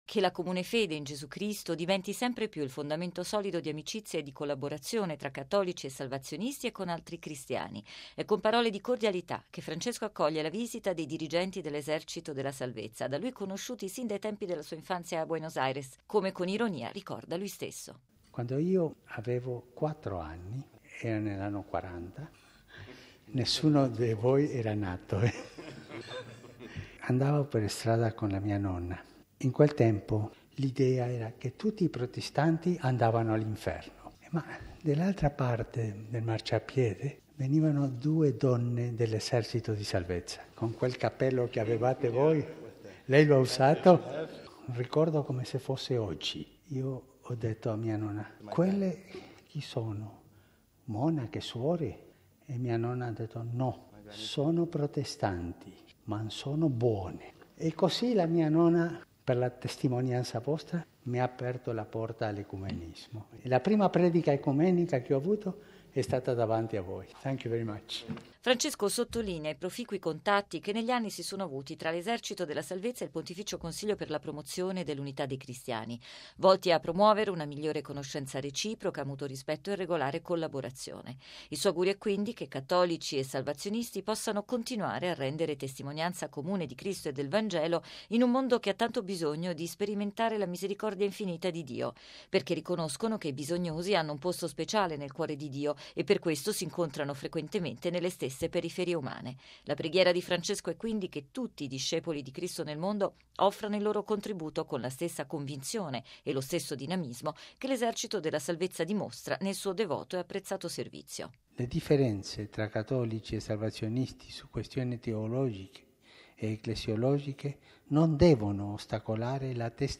Papa Francesco ha ricevuto oggi in Vaticano una delegazione dell’Esercito della Salvezza, movimento internazionale evangelico fondato nel 1865 e presente in oltre 120 Paesi, che promuove, insieme alla predicazione della Parola di Dio, opere sociali a favore dei più poveri. Servizio